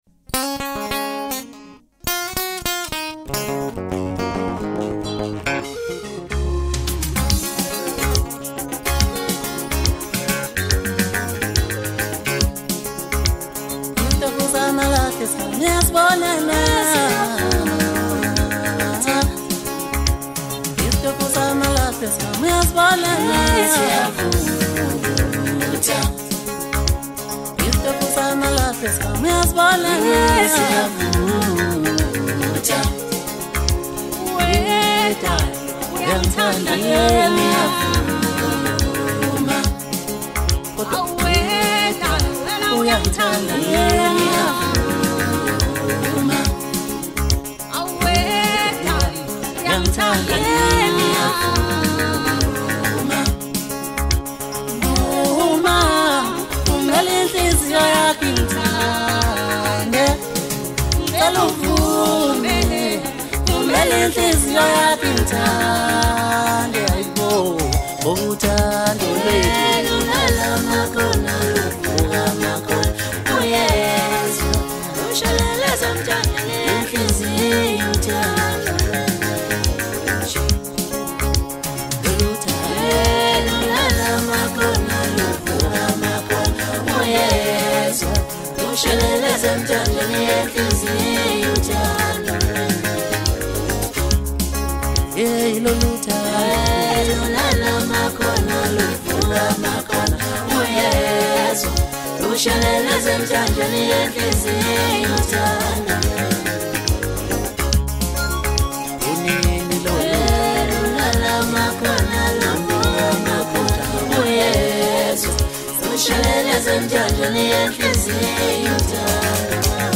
Talented vocalist
with catchy hooks, smooth flow, and consistent replay value